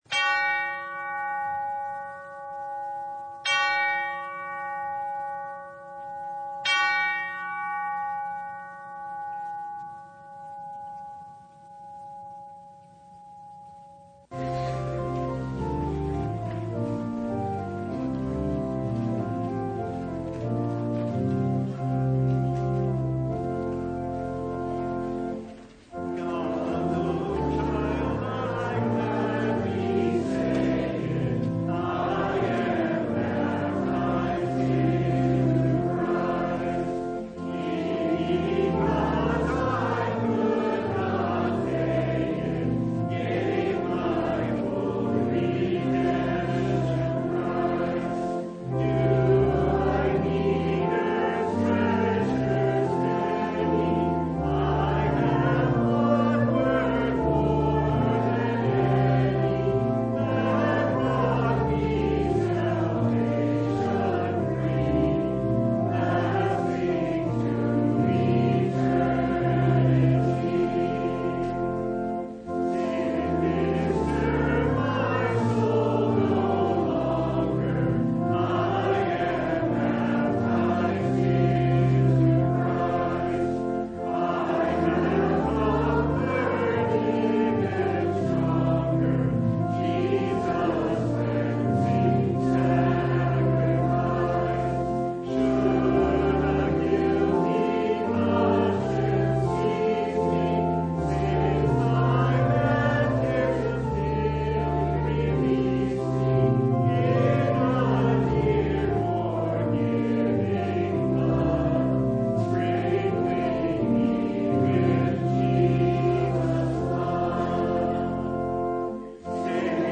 Mark 10:17-22 Service Type: Sunday It’s heartbreaking